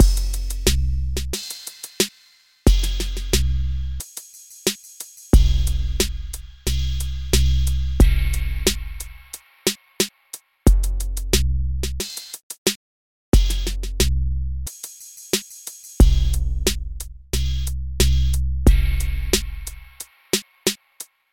小军鼓 " KIK 008
描述：低音鼓前面有一个小鼓，不同的打击和不同的麦克风混合
标签： 低音 小鼓
声道立体声